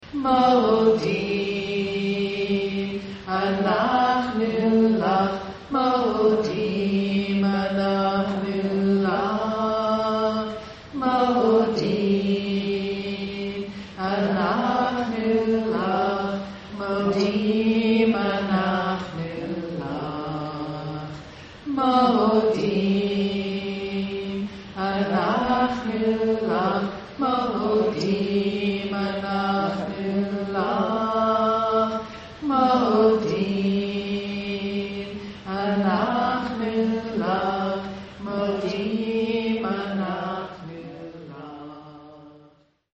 For more than 15 years we met monthly in the Reutlinger Community Synagogue.